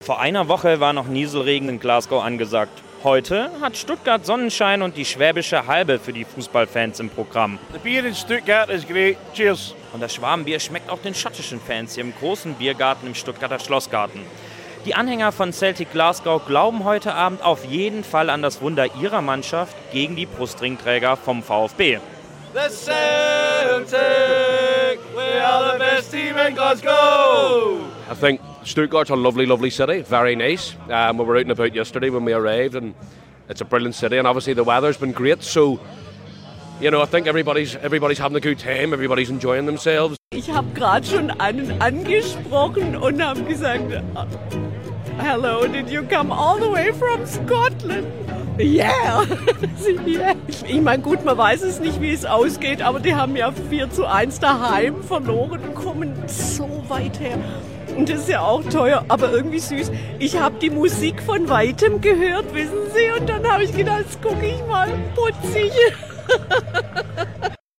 Das Bier in Stuttgart sei großartig, lobt ein schottischer Fan im Gespräch mit einem SWR-Reporter am Nachmittag.